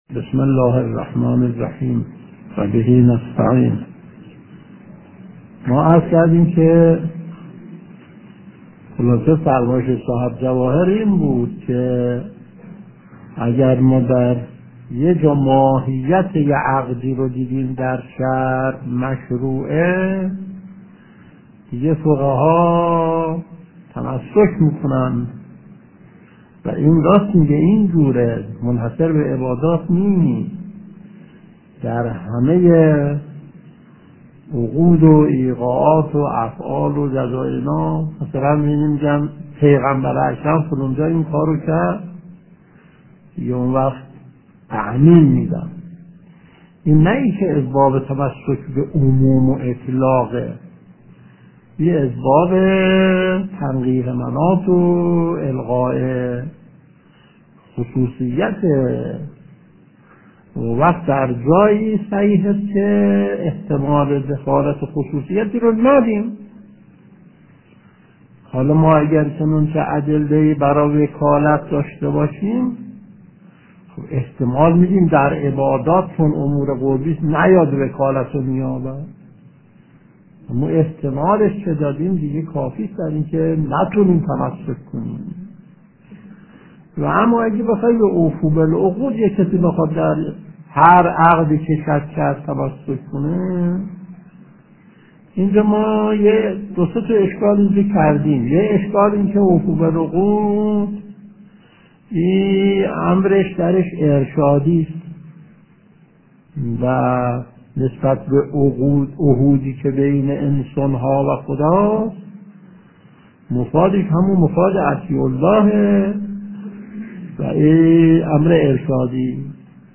درس 629 : (26/8/1370)